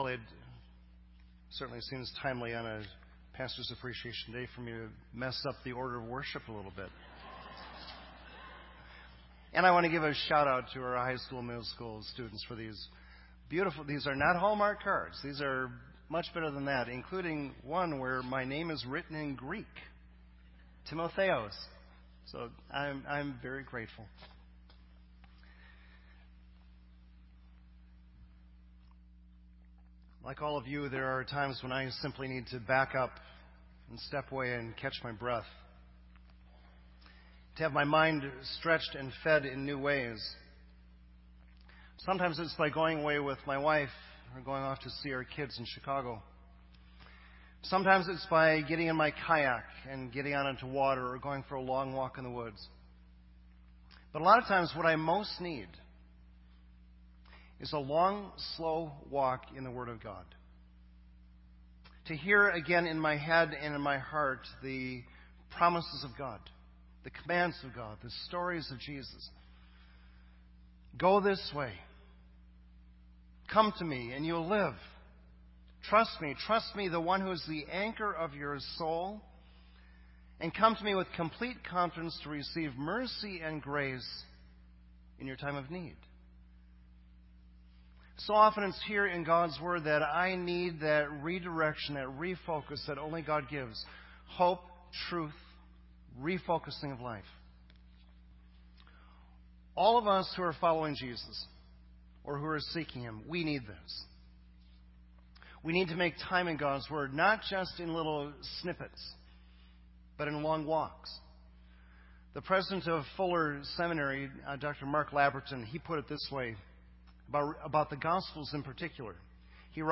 This entry was posted in Sermon Audio on November 3